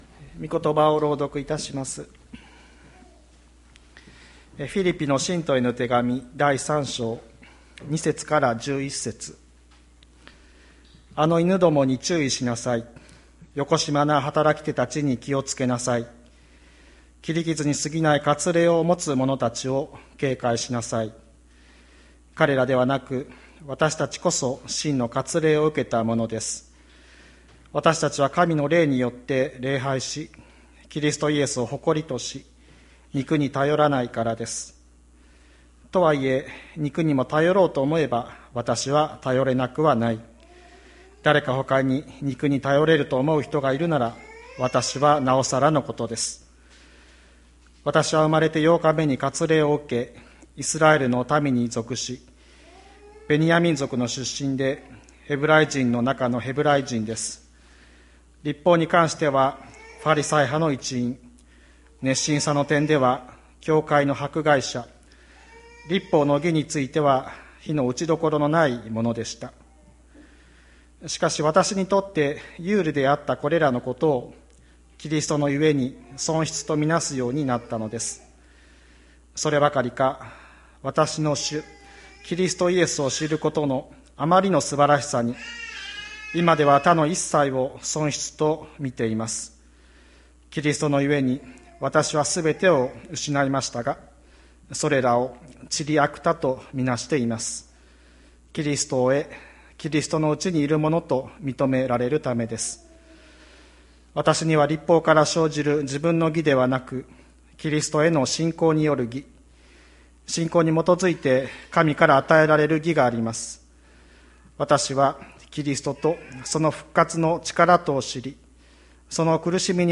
2021年06月13日朝の礼拝「教会で知るすばらしいこと」吹田市千里山のキリスト教会
千里山教会 2021年06月13日の礼拝メッセージ。